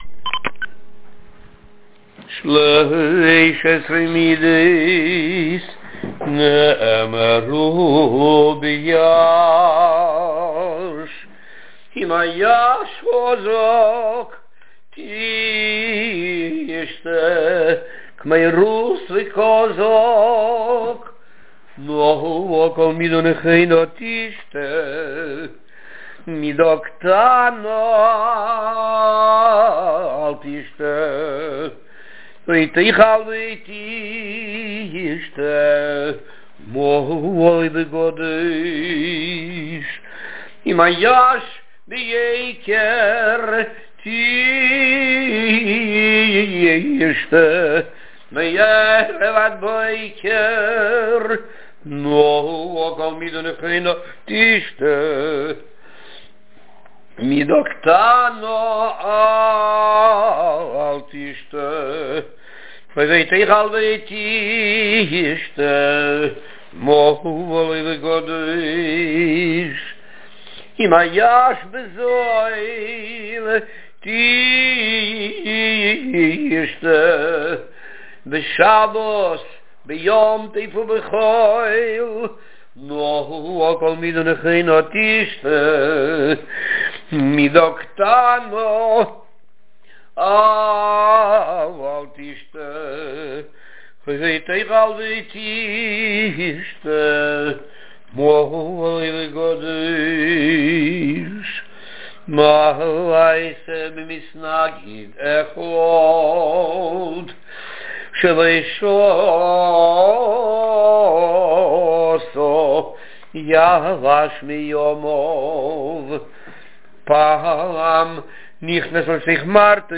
ניגון שלוש עשרה מידות נאמרו ביי"ש ניגון חסידי שנהגו לזמר חסידי חב"ד בסמרקנד בהתוועדויות חג הפורים ובשמחת תורה.
הניגון מושר בנעימה של סליחות בהשאלת המילים ' שלוש עשרה מידות נאמרו בחנינה' ...